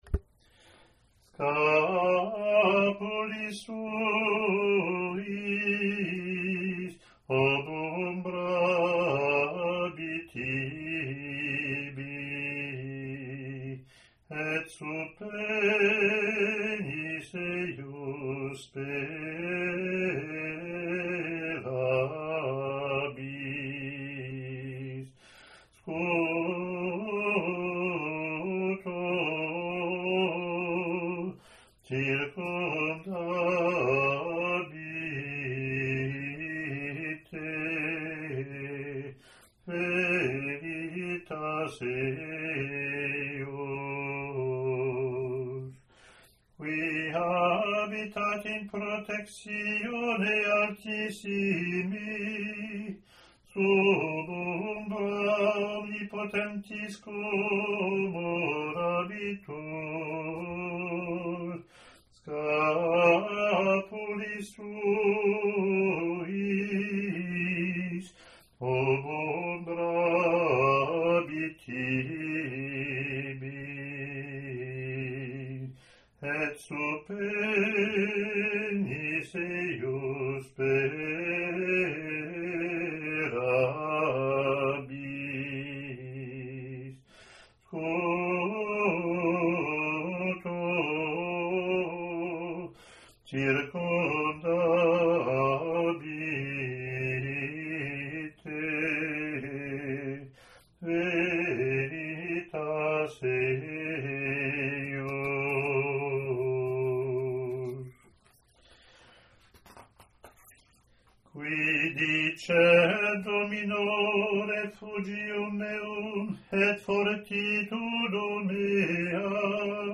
Latin antiphon)
lt01-comm-gm.mp3